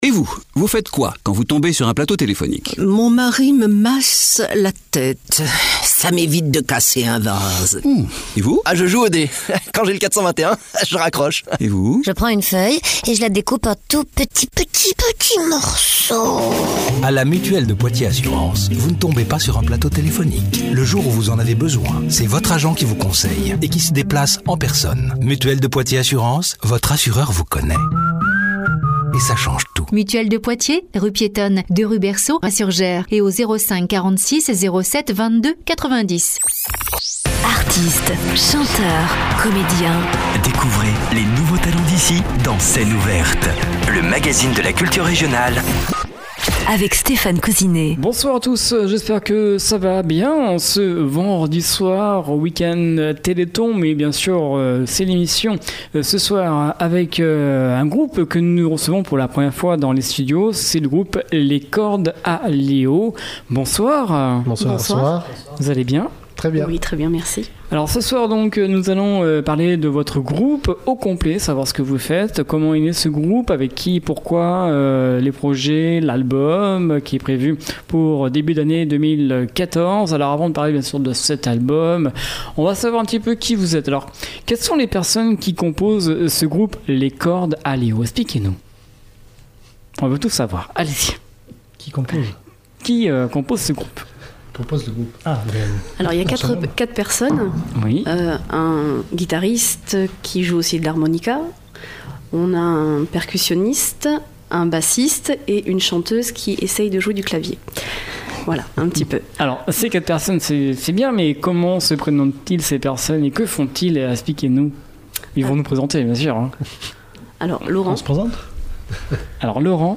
Le groupe Les Cordes à Léo de Saint-Junien(87) sera l’invité de l’émission Scène ouverte vendredi 6 décembre à 20h.